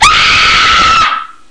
The Maze Scream - Botón de Efecto Sonoro